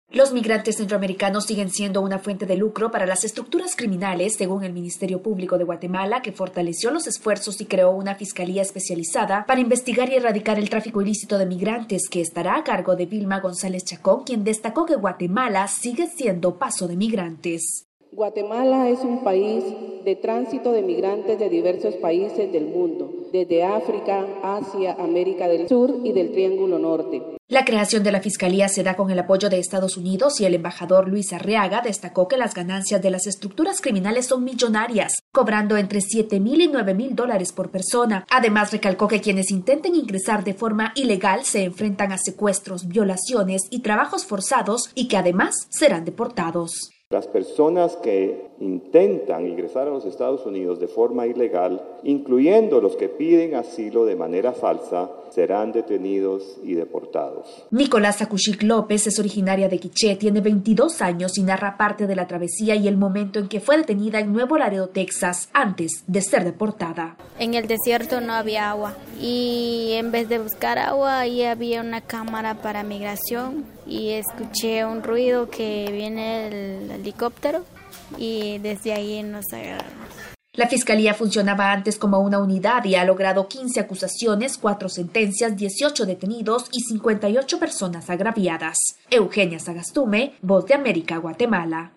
VOA: Informe desde Guatemala